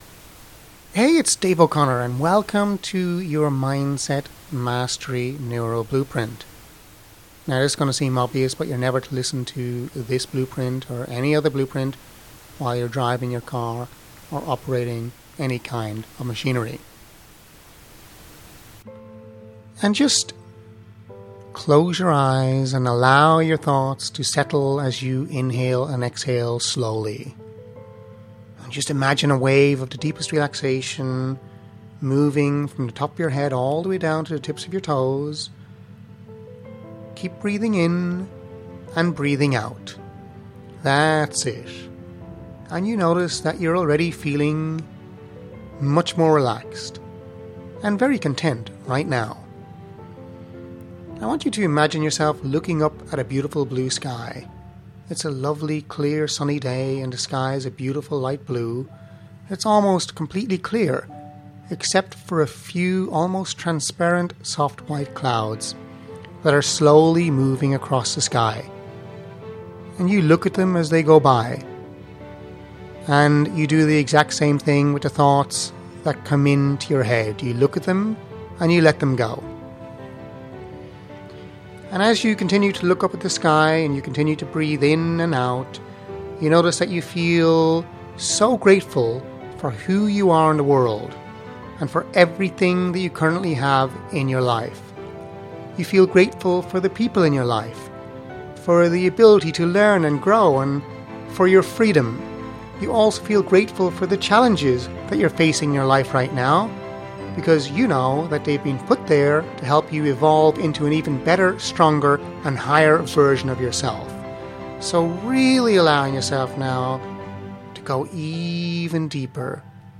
The combination of relaxation, active meditation, mental rehearsal, hypnotherapy, neuro-repatterning phrases and just allowing our voices to guide you, along with beautifully composed musical scores has been proven over and over again with thousands of our clients worldwide to accelerate their success much faster than they can by themselves - taking their business and life to a whole new level.